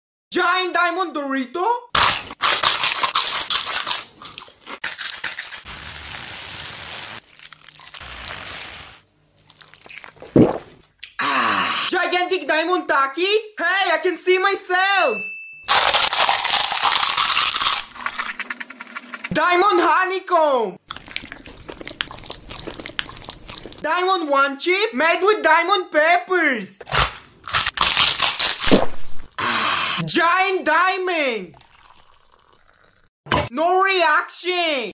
Spicy Diamond Food ASMR!_ 🥵 sound effects free download